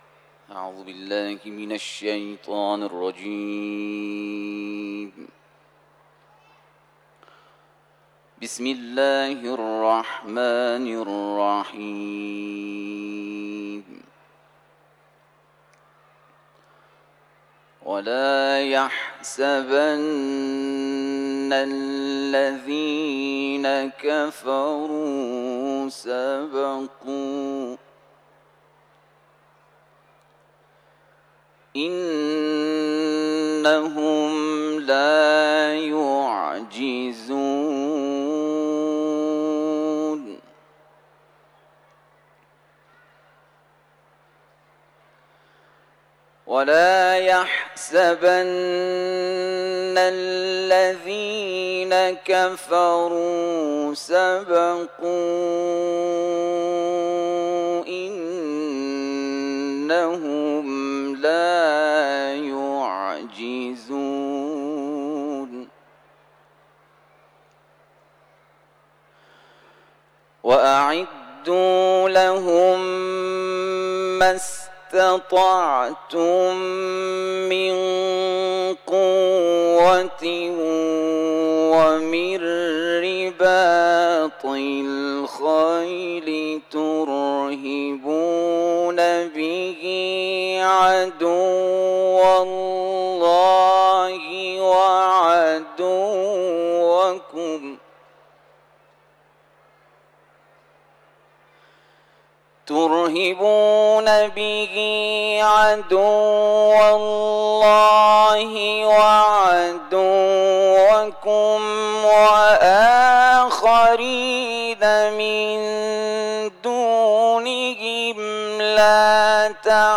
، آیات 59 تا 63 سوره «انفال» و نیز آیاتی از سوره «تین» را در جوار بارگاه نورانی رضوی تلاوت کرده است.
تلاوت